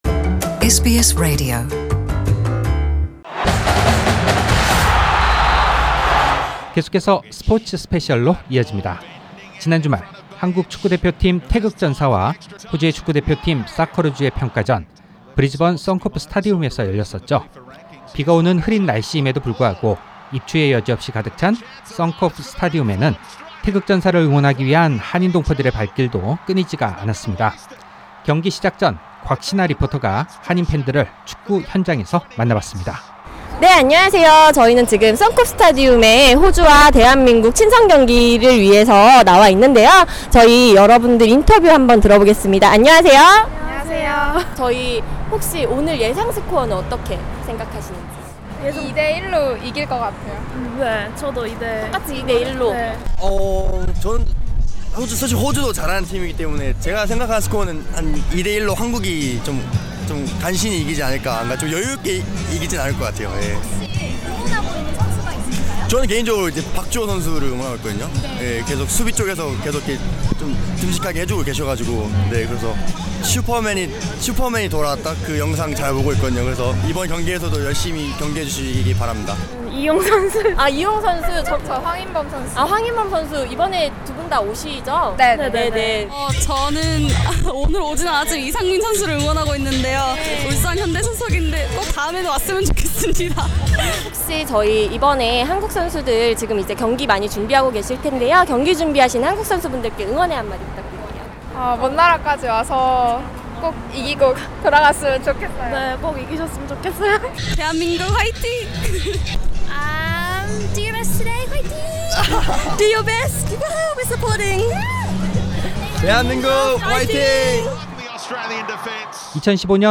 상단의 오디오 다시 듣기(팟캐스트)를 클릭하시면, 황의조, 구자철, 이영표 해설 위원과의 인터뷰 내용을 다시 들을 수 있습니다.